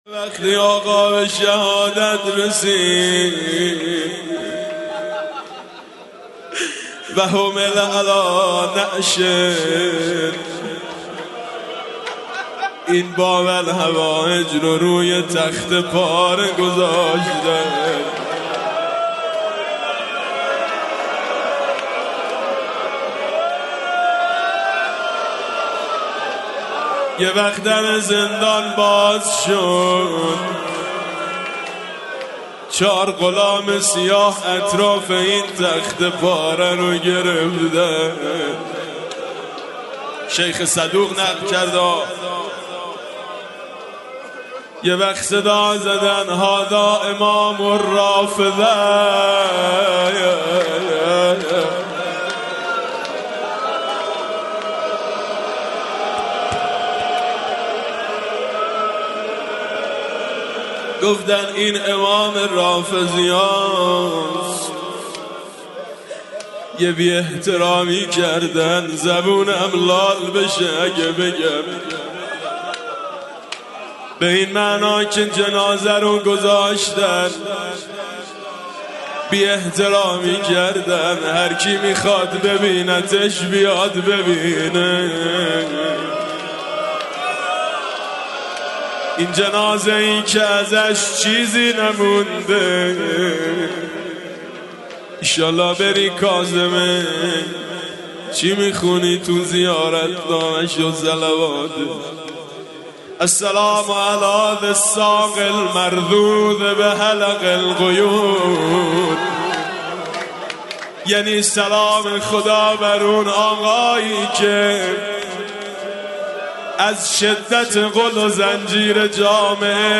مداحی حاج میثم مطیعی به مناسبت شهادت امام موسی کاظم(ع)